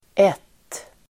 Uttal: [et:]